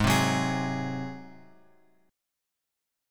G#7 chord {4 3 4 x x 4} chord
Gsharp-7th-Gsharp-4,3,4,x,x,4.m4a